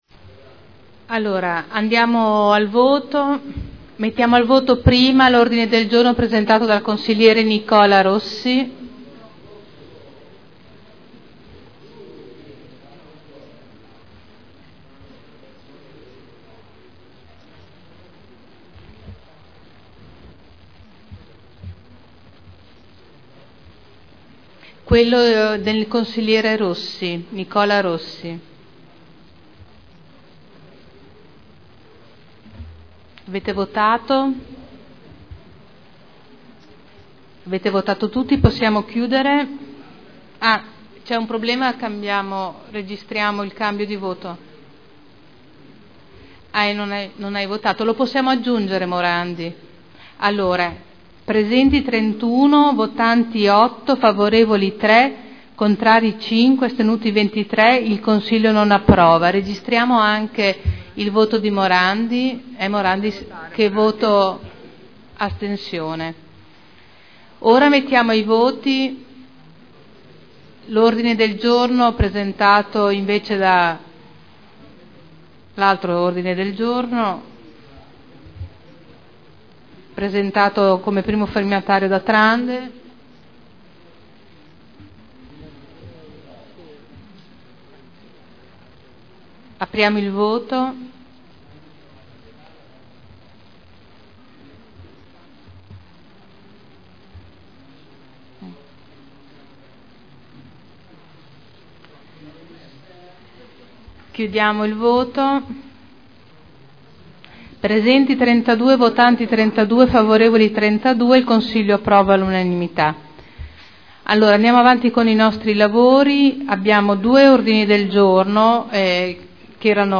Seduta del 22/11/2010 Ordine del Giorno presentato dai consiglieri Trande, Morandi, Ricci, Ballestrazzi e Torrini: alluvione nelle provincie venete di Vicenza, Verona, Padova,Treviso e Belluno, solidarietà, vicinanza e misure concrete di tipo fiscale ed economico per le popolazioni coinvolte Ordine del Giorno presentato dai consiglieri Nicola Rossi Stefano Barberini e Mauro Manfredini : gettone dei consiglieri agli alluvionati del veneto Votazioni